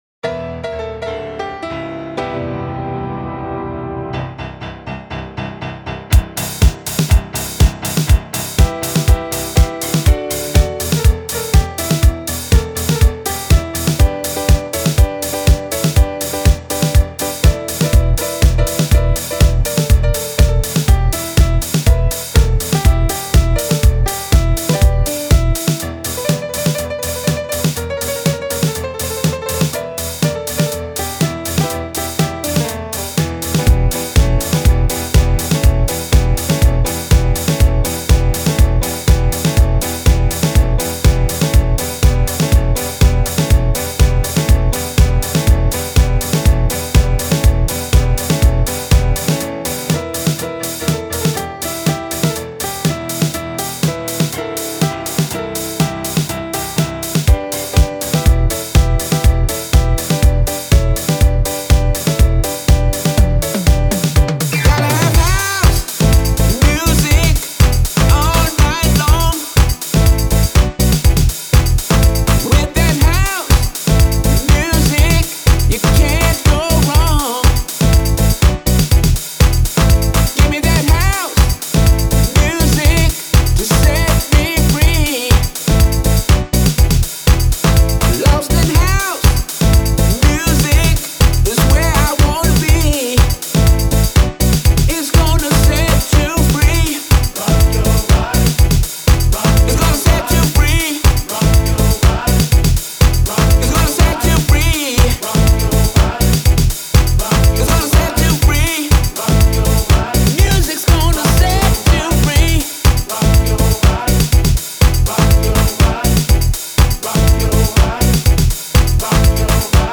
classic house music anthem